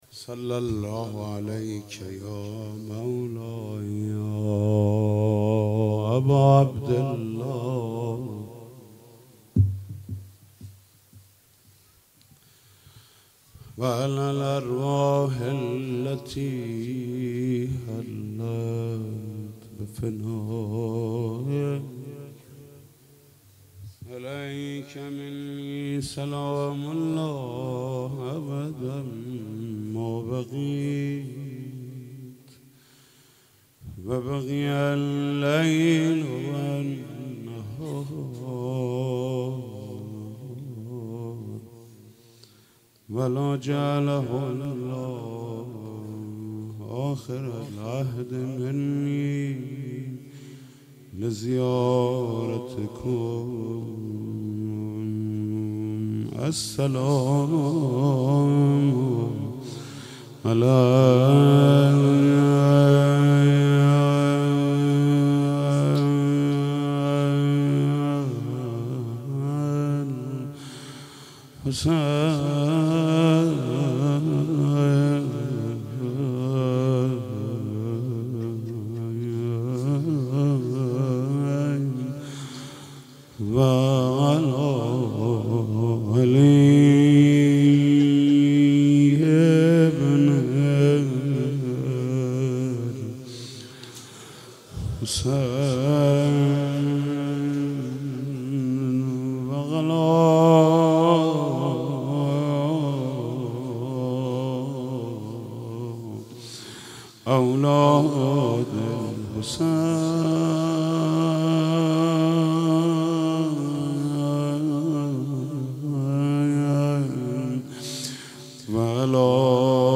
اربعین - روضه - عزار نیلی و قد خم و چشم تر آوردم حسین